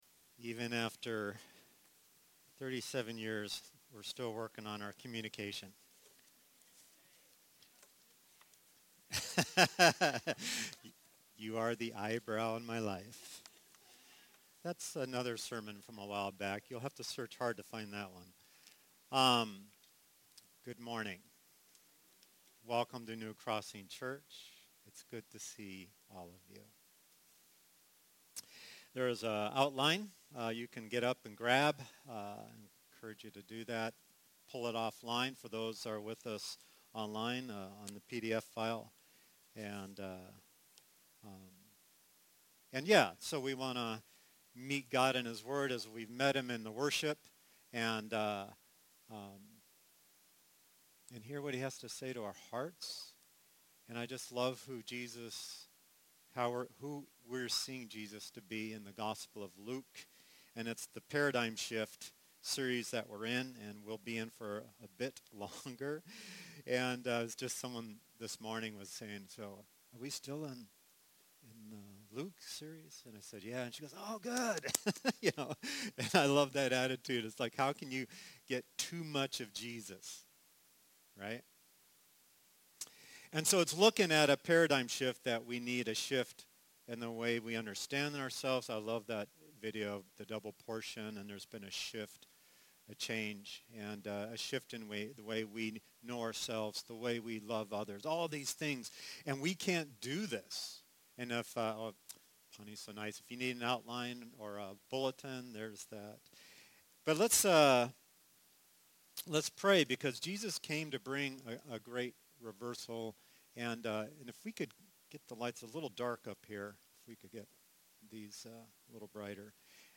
Unfortunately we had difficulties with the recording this week, so this only contains the first half of the sermon.